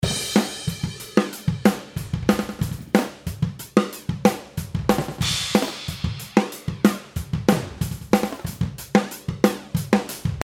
今回、ドラムにかけてみたんですけど、いいか悪いかは別としてわかりやすくはありました。
最後に周期をポジティブだけでなく、ネガティブも目一杯広げてみましょう。
ネガティブからスタートしてポジティブへ向かい、再びネガティブに戻る感じです。